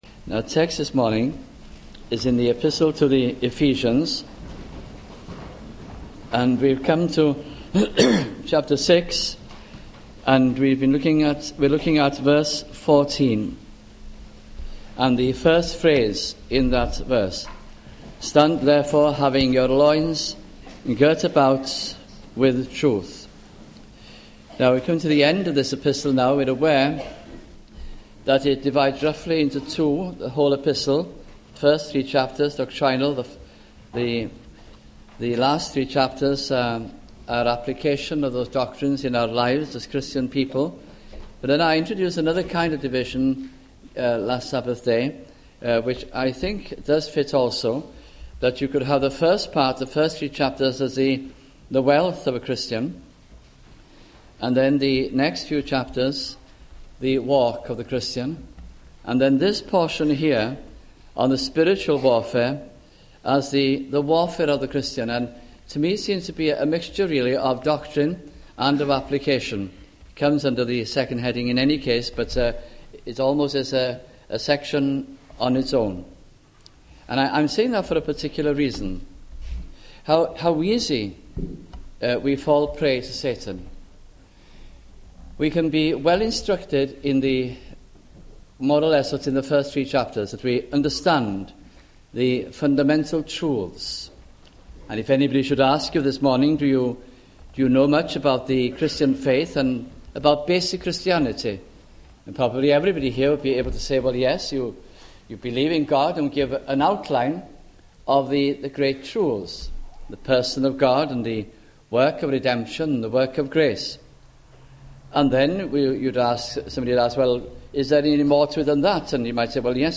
» Ephesians Series 1991 » sunday morning messages